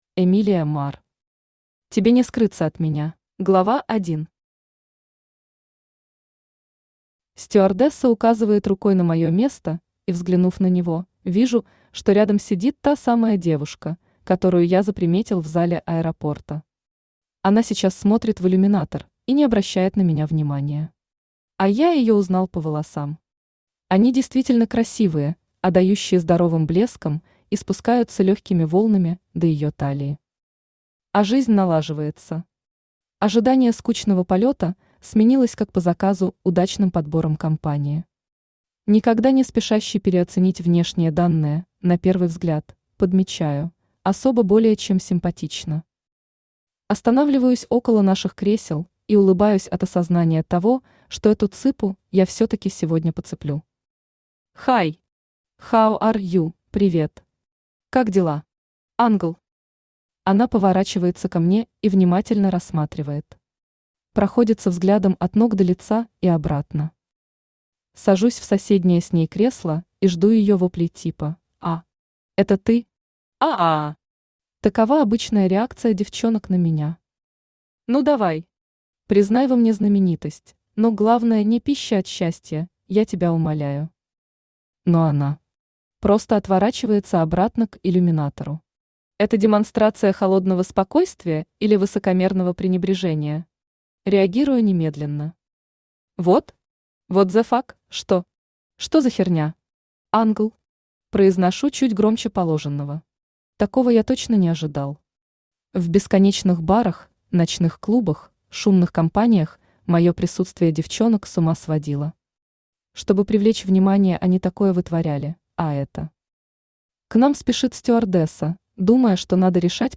Аудиокнига Тебе не скрыться от меня | Библиотека аудиокниг
Aудиокнига Тебе не скрыться от меня Автор Эмилия Марр Читает аудиокнигу Авточтец ЛитРес.